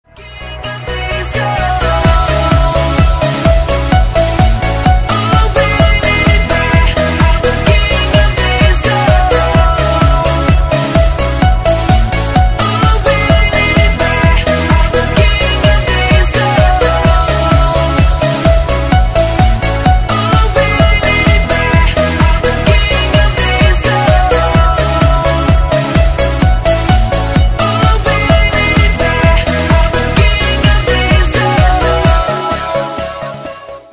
DANCE хит прошлых лет под рингтон